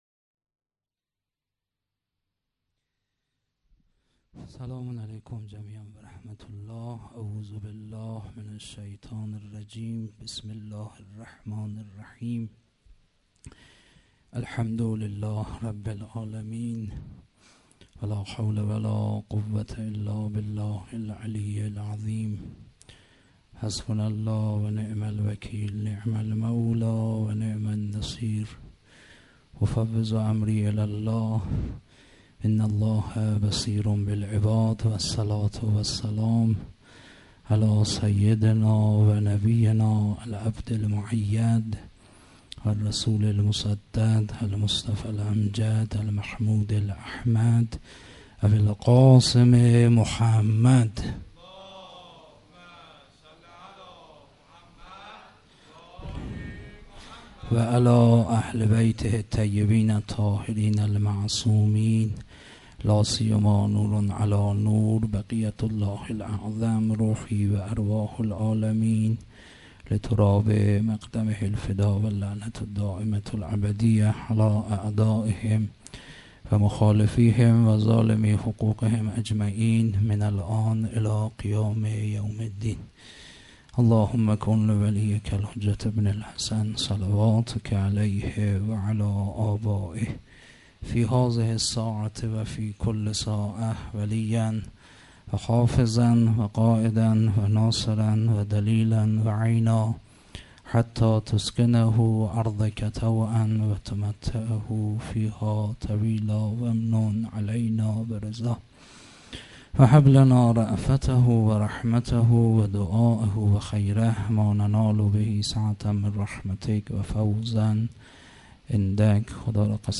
هیئت مکتب الزهرا(س)دارالعباده یزد
محرم1442_شب سوم